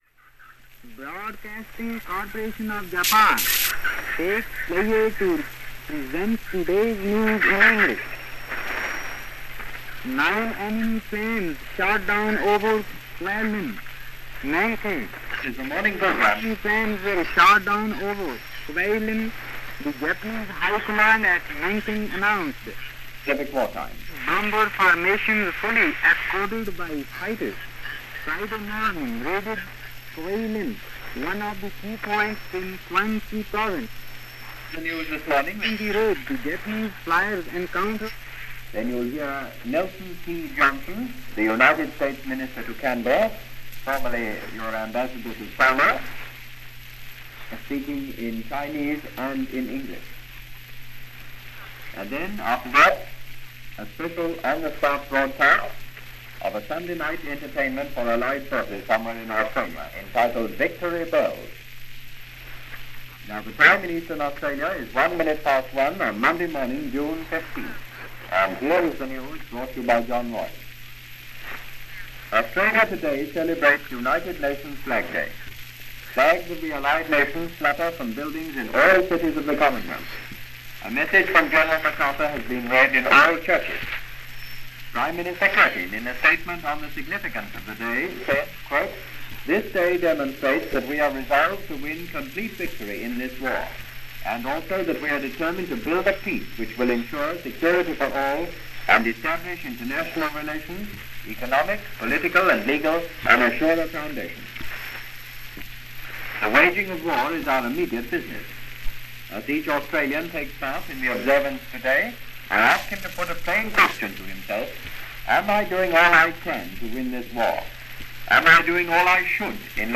Radio Tokyo – Radio Australia – News reports – June 14/15, 1942 – Gordon Skene Sound Collection –